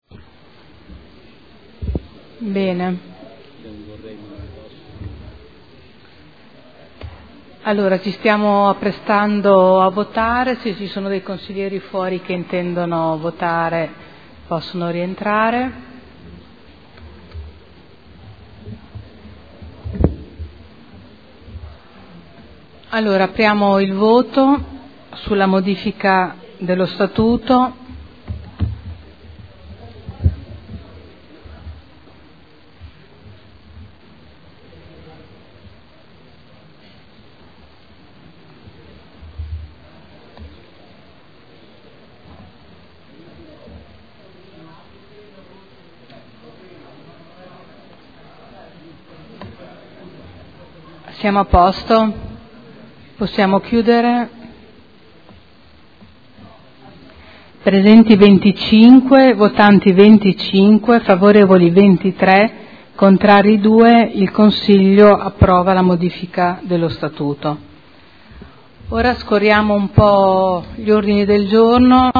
Seduta del 6 marzo. Proposta modifiche allo Statuto comunale – Approvazione.